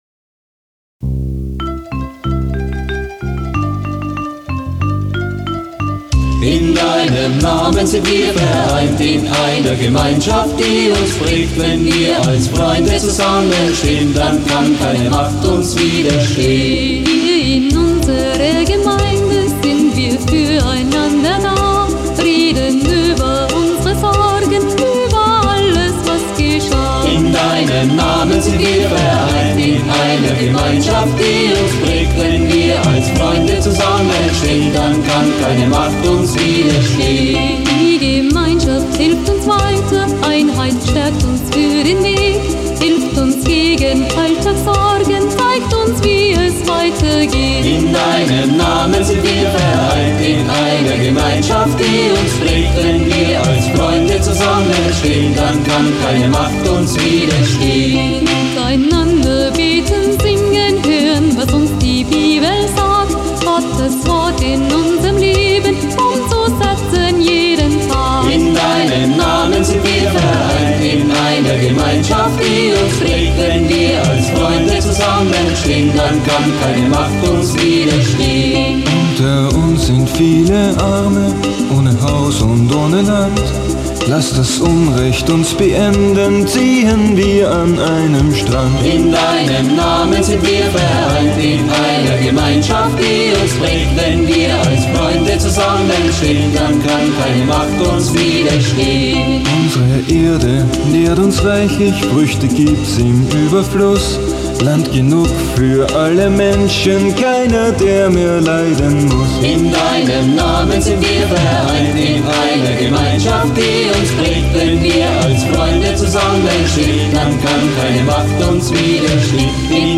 Musik: Traditional (Brasilien)